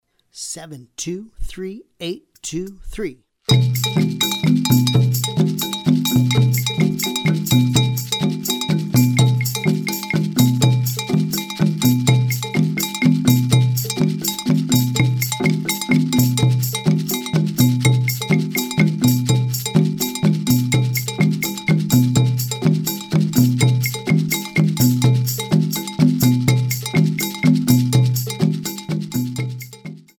The music combines various percussion instruments,
Medium Triple Meter
Medium Triple Meter - 125 bpm